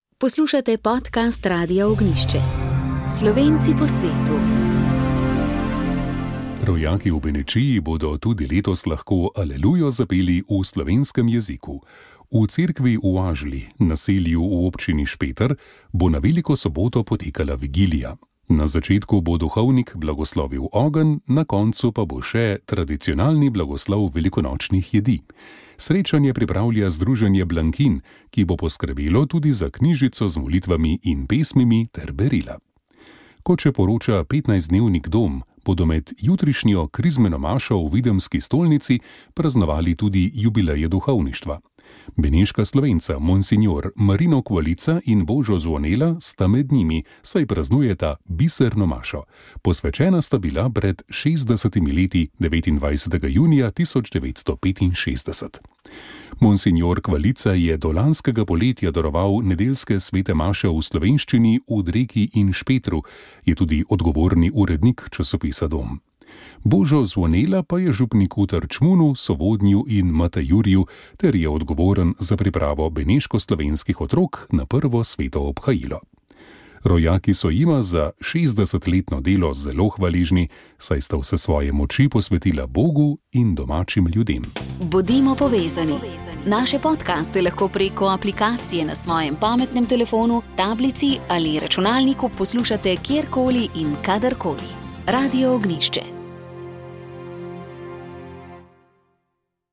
Znanstveni posvet ob 100 letnici rojstva Alojza Rebule - 4
Slovenska akademija znanosti in umetnosti je ob stoti obletnici rojstva akademika Alojza Rebule pripravila znanstveni posvet z naslovom Alojz Rebula: Slovenec med zgodovino in nadčasnim.